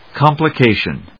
音節com・pli・ca・tion 発音記号・読み方
/kὰmpləkéɪʃən(米国英語), k`ɔmpləkéɪʃən(英国英語)/